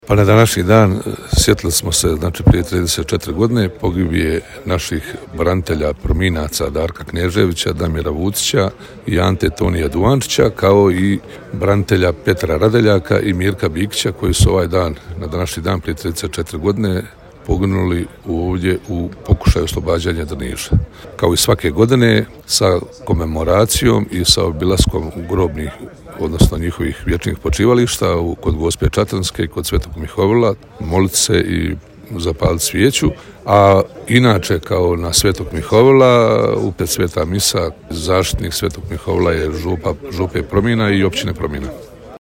Načelnik Općine Promina, brigadni general Tihomir Budanko: